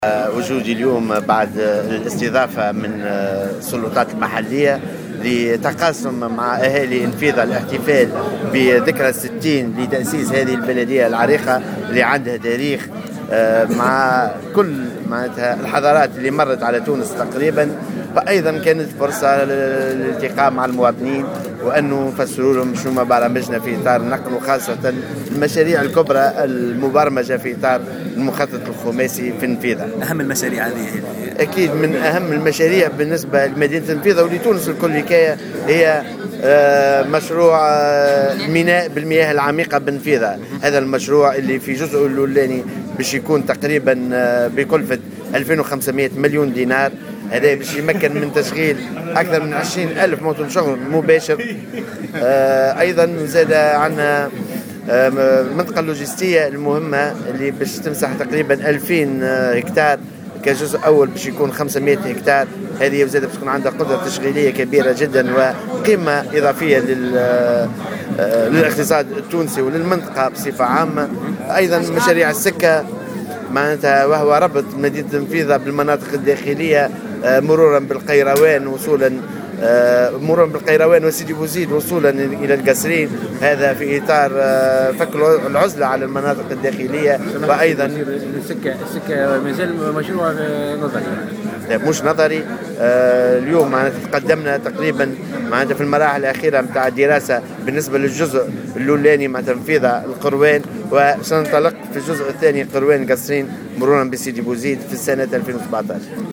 وذكّر في تصريحات صحفية على هامش زيارة أداها إلى النفيضة لإحياء الذكرى الستين لاحداث بلدية النفيضة بأن هذا المشروع سيربط النفيضة بالمناطق الداخلية مرورا بالقيروان وسيدي بوزيد وصولا الى القصرين لفك العزلة عن المناطق الداخلية، بحسب تعبيره.